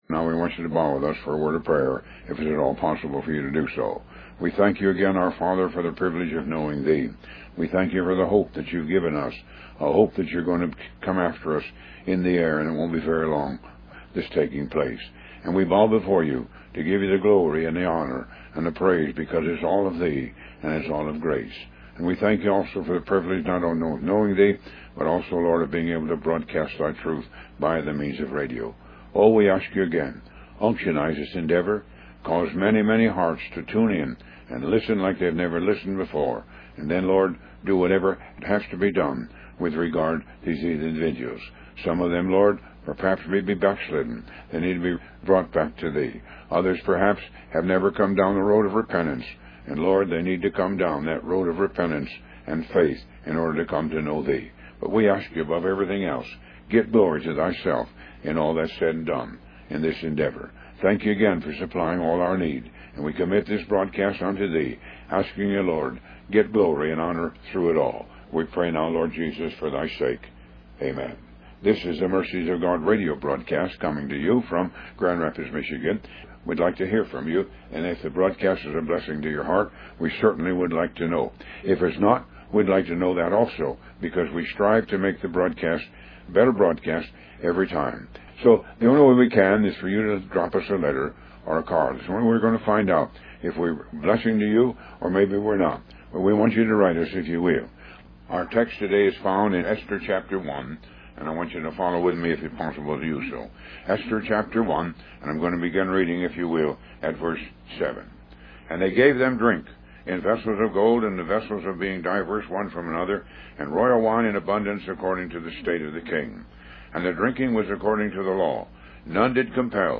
Talk Show Episode, Audio Podcast, Moga - Mercies Of God Association and The Decree Of The King on , show guests , about The Decree Of The King, categorized as Health & Lifestyle,History,Love & Relationships,Philosophy,Psychology,Christianity,Inspirational,Motivational,Society and Culture